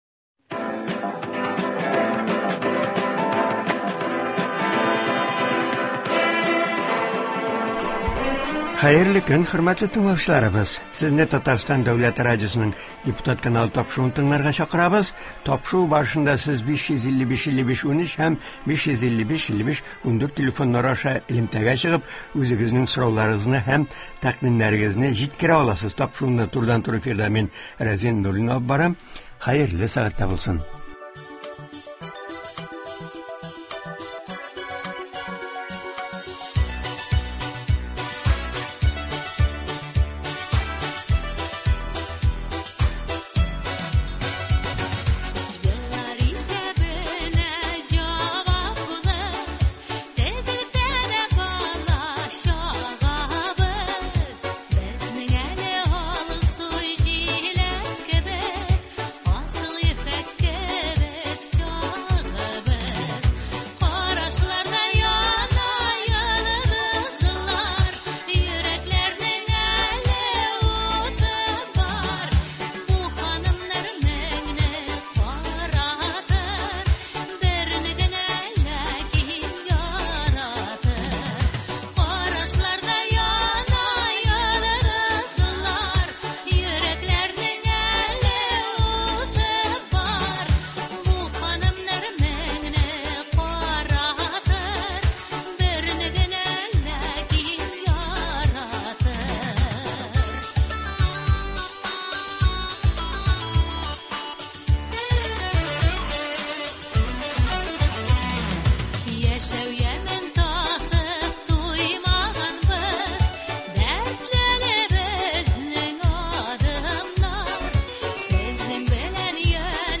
Очрашулар вакытында күтәрелгән мәсьәләләр хакында турыдан-туры эфирда Татарстан республикасы Дәүләт Советы депутаты Римма Ратникова сөйләячәк, тыңлаучылар сорауларына җавап бирәчәк.